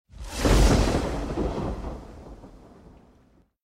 eff_thunder.mp3